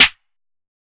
{Snare} Smack!.wav